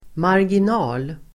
Uttal: [margin'a:l]